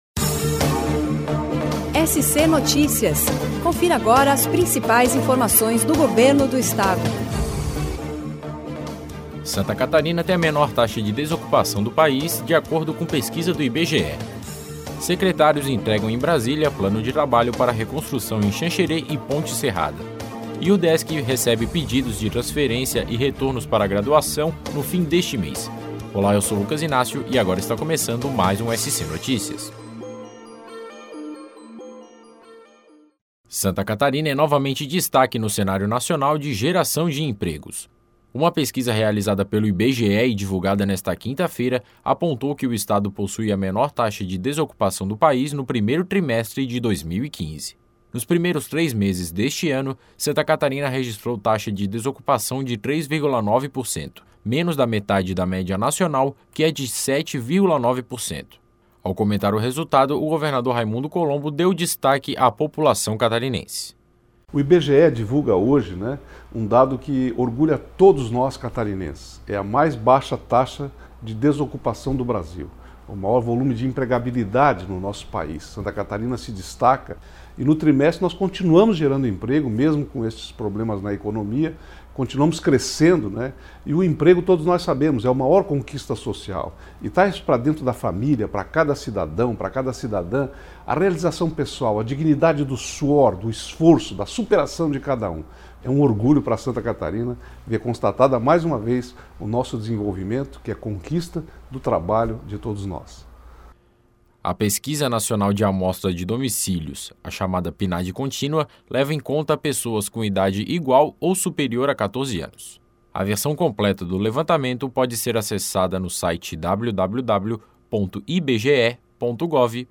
O SC Notícias é um programa produzido diariamente pelo Serviço de Rádio da Secretaria de Estado da Comunicação com as principais informações do Governo do Estado.